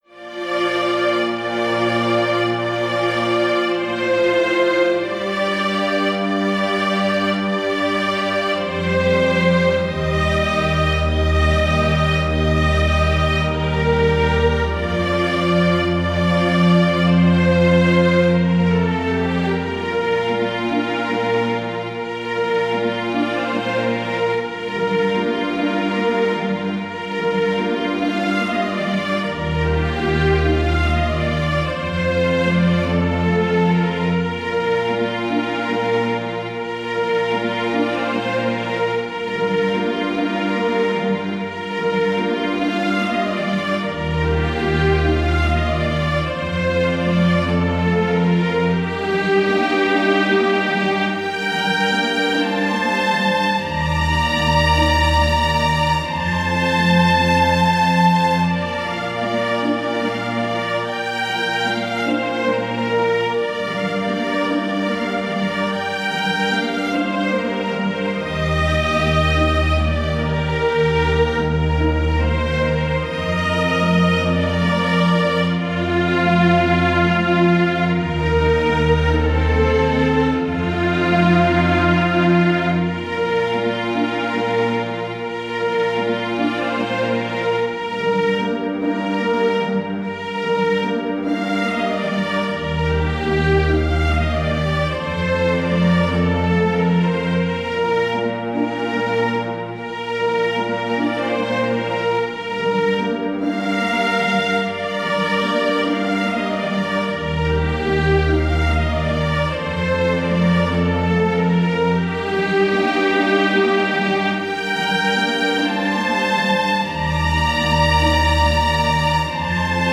● Violino I
● Violino II
● Viola
● Violoncelo